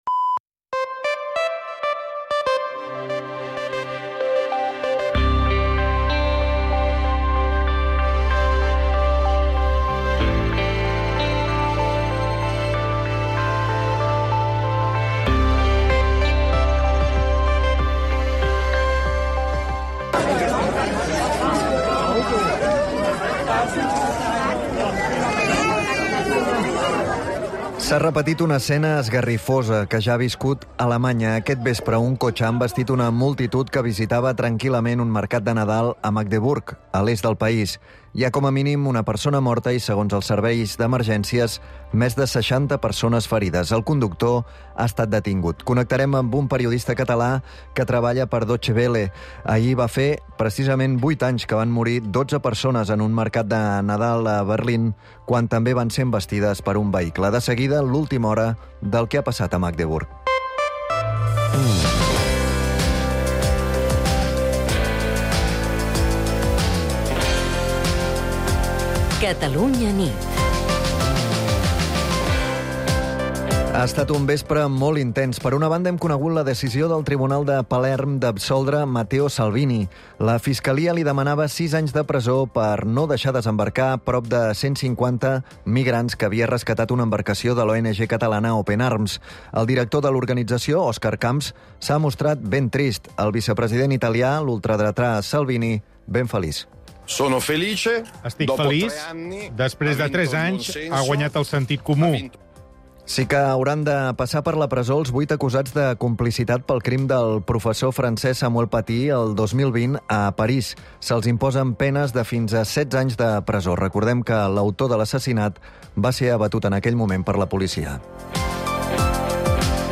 … continue reading 411 つのエピソード # 21-22 # Corporaci Catalana de Mitjans Audiovisuals, SA # Catalunya Rdio # News Talk # News